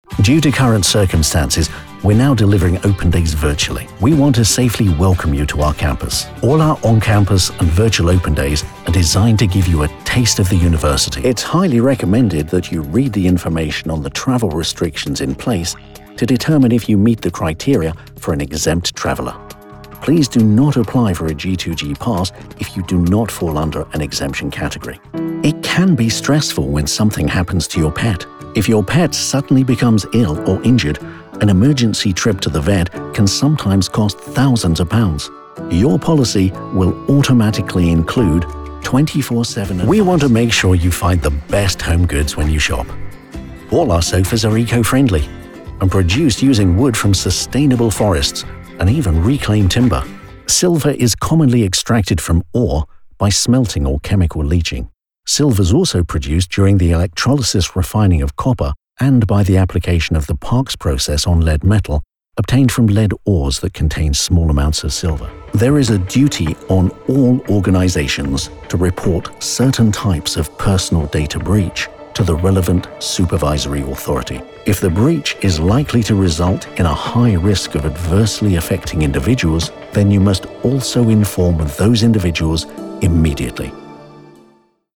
Calm, Credible, Friendly - British male voiceover
Commercial Mix
English RP
Middle Aged
... Record broadcast-quality sound using professional studio equipment;
British male with a reassuringly clear, warm, and engaging neutral RP voice.